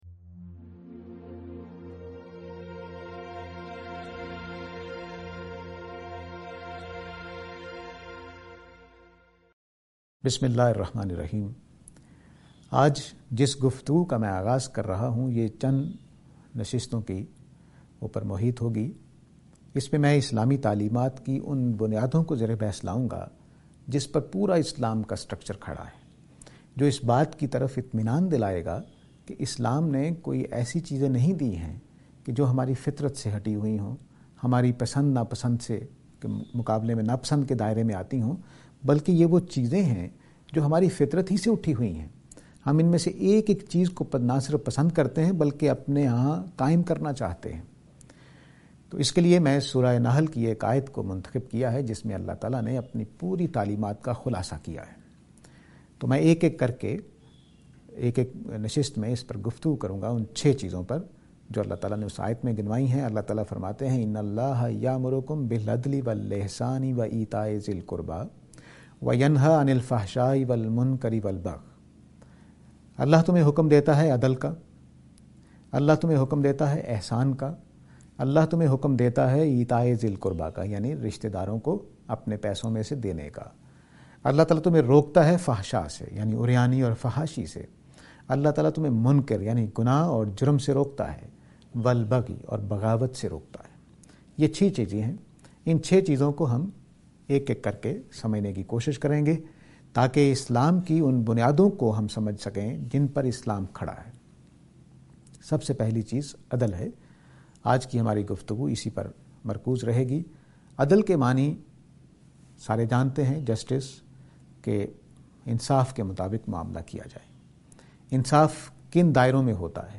This lecture is and attempt to answer the question "Justice".